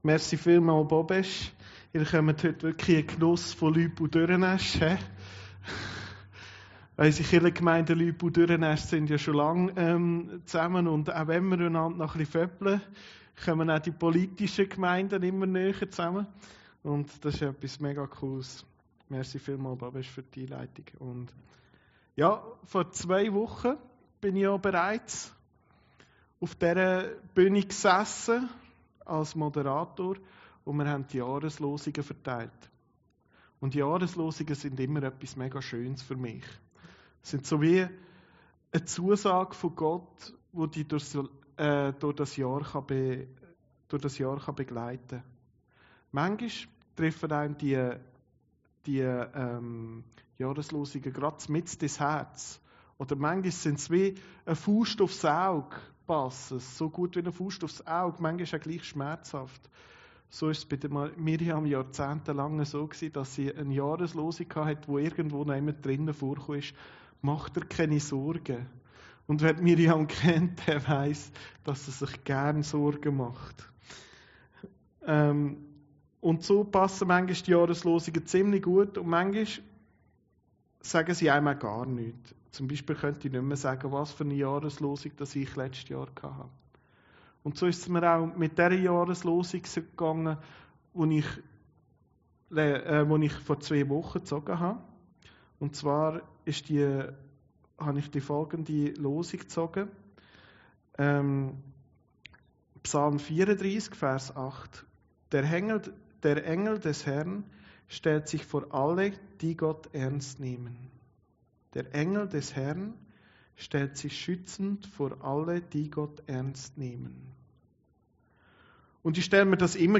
Predigten Heilsarmee Aargau Süd – Jakob